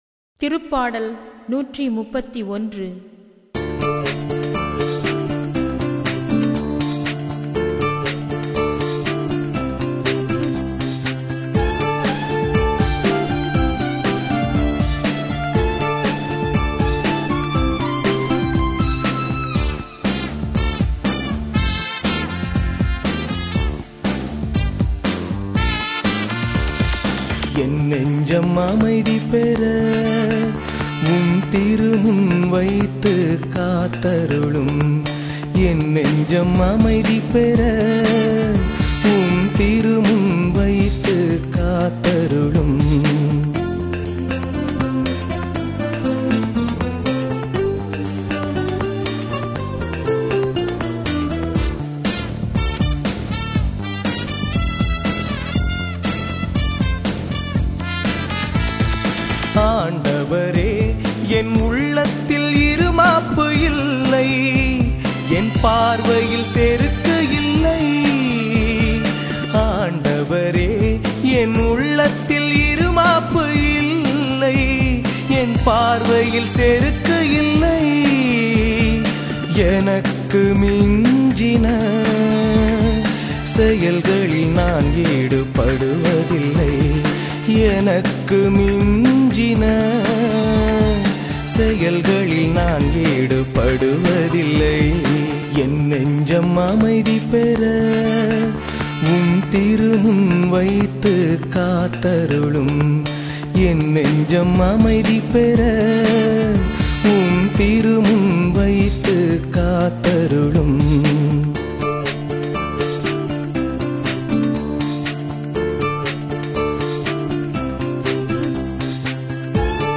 பதிலுரைப் பாடல் -